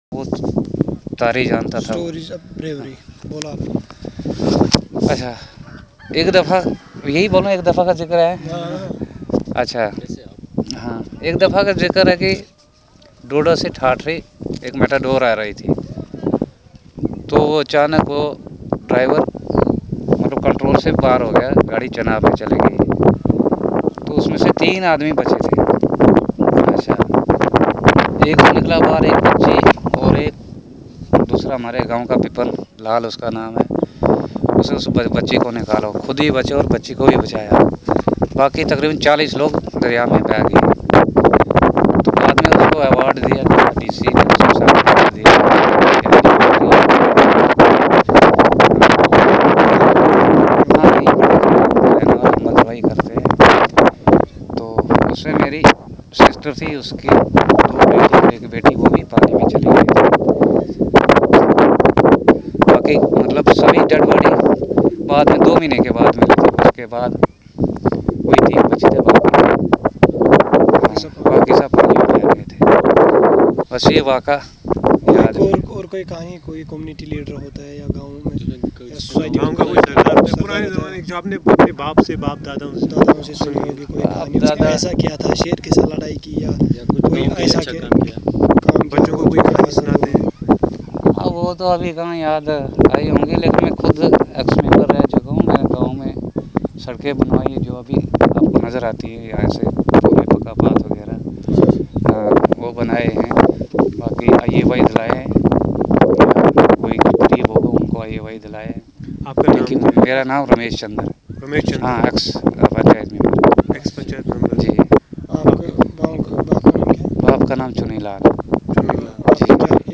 Conversation about ghost stories